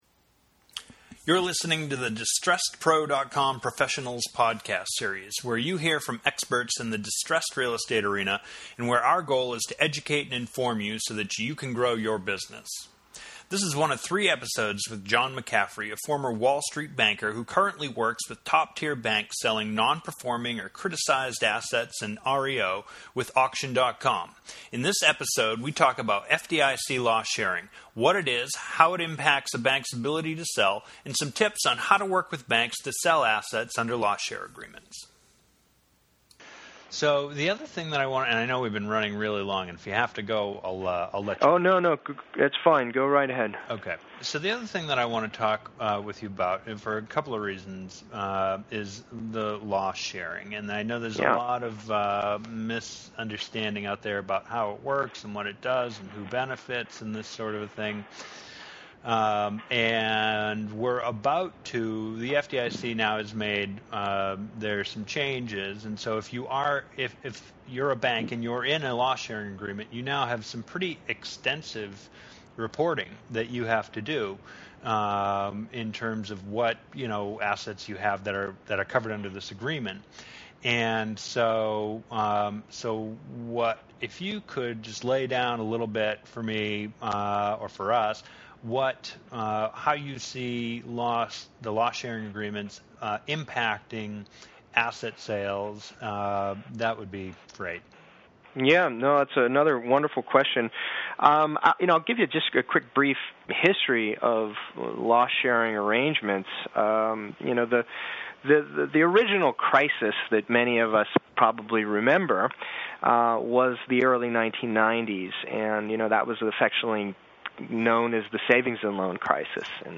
Podcast: How Loss Share Agreements Impact Distressed Asset Deal Flow This is the second in a 3-part podcast interview series